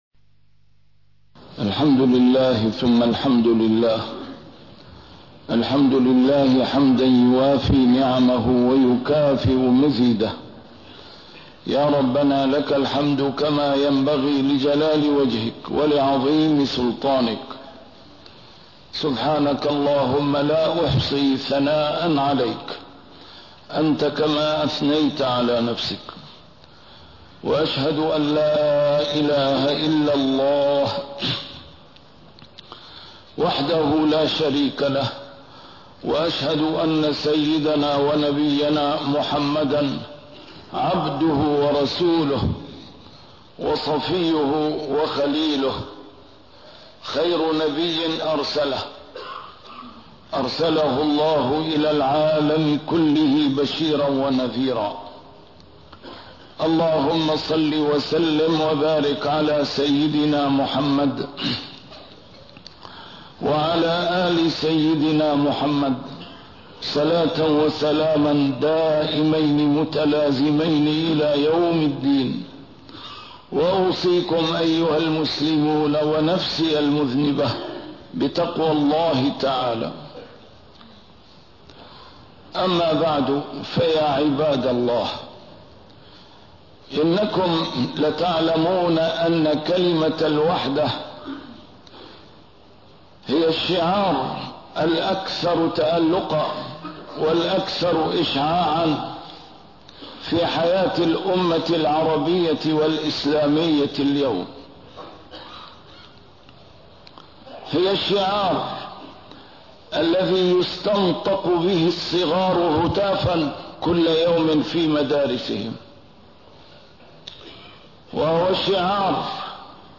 A MARTYR SCHOLAR: IMAM MUHAMMAD SAEED RAMADAN AL-BOUTI - الخطب - وحدة الأمة بين الشعار والتطبيق